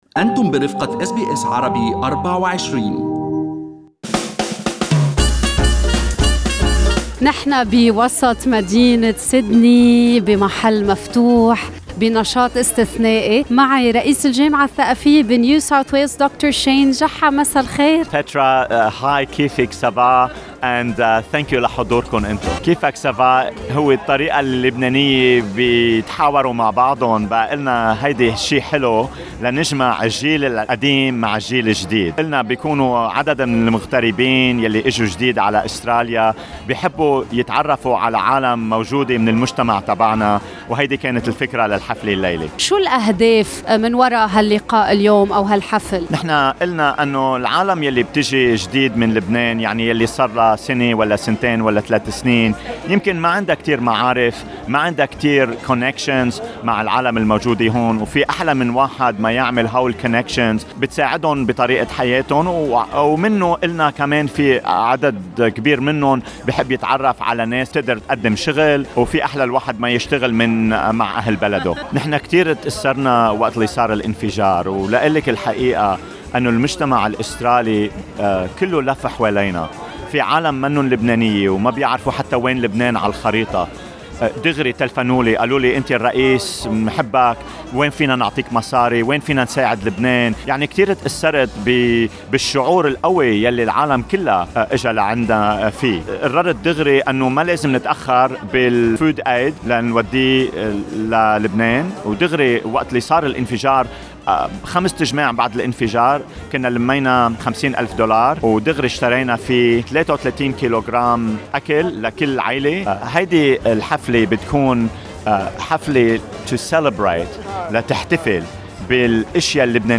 Source: WLCUSI للإستماع إلى آراء الشبيبة ولعيش أجواء هذا اللقاء، يمنكم الإستماع إلى هذا التقرير الصوتي أعلاه.